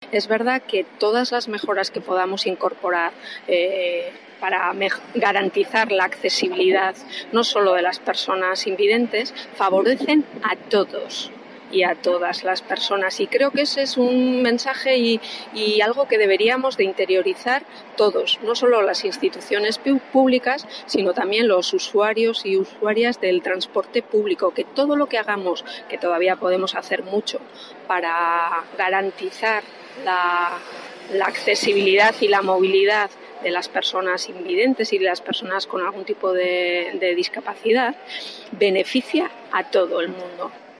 al trasladarse a ciegas en el Euskotren formato MP3 audio(0,15 MB); y la diputada de Movilidad y Ordenación del Territorio de la provincia, Marisol Garmendia, advertía de que todas las mejoras en accesibilidad redundan no sólo en las personas con discapacidad,